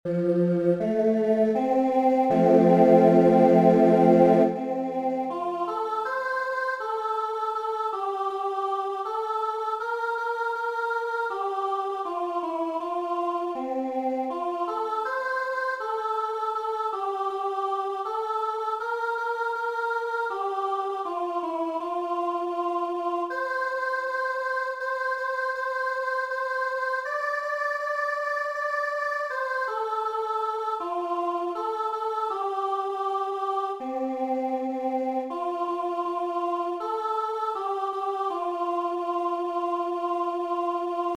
Soprano Audio Part
Solid-Rock-Sopranos.mp3